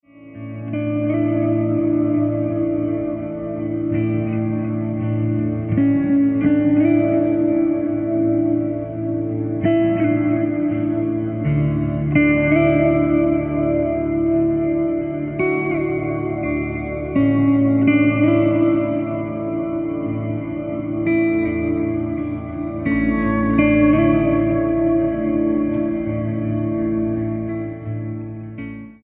STYLE: Rock
ventures into ambient territory
begins in ethereal fashion